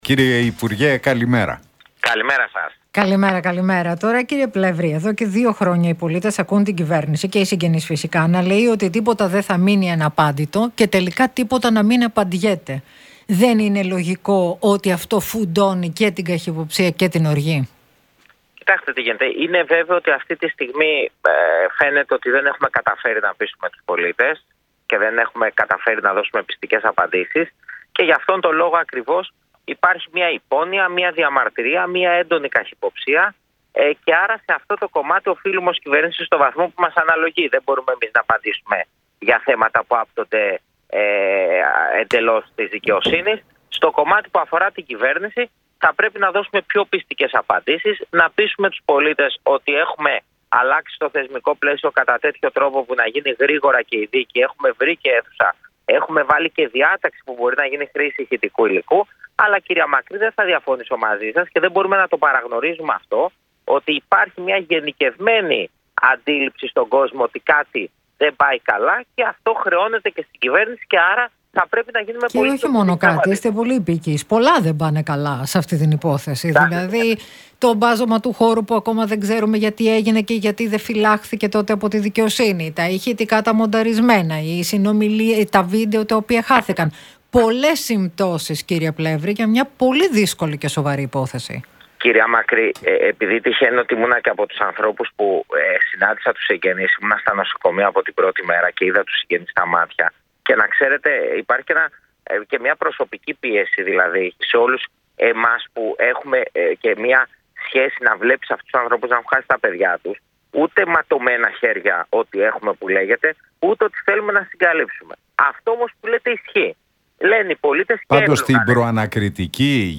Για τα Τέμπη και το συλλαλητήριο της Κυριακής τοποθετήθηκε ο Κοινοβουλευτικός Εκπρόσωπος της ΝΔ, Θάνος Πλεύρης στον Realfm 97,8 και στους Νίκο Χατζηνικολάου